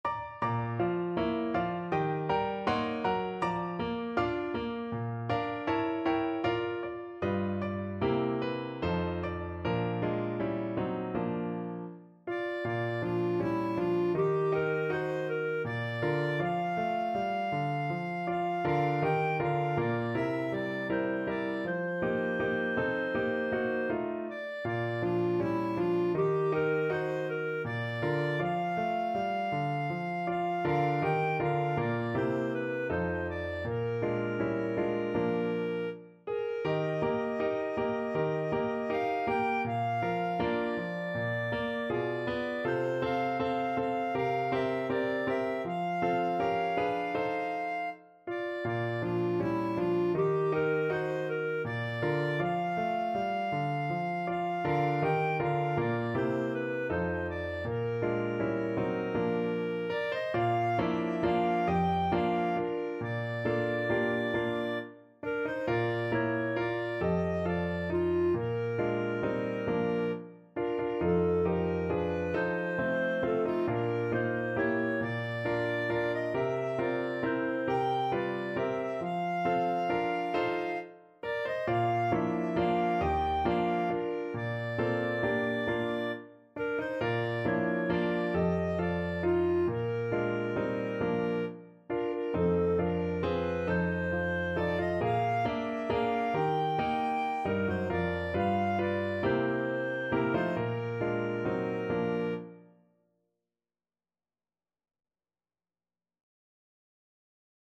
4/4 (View more 4/4 Music)
Andante
Pop (View more Pop Clarinet Music)